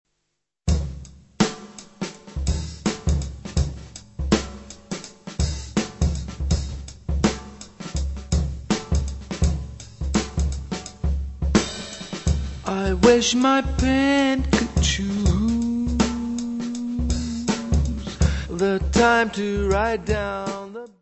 voz e trompete
contrabaixo
bateria.
Music Category/Genre:  Jazz / Blues